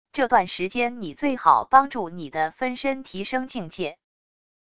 这段时间你最好帮助你的分身提升境界wav音频生成系统WAV Audio Player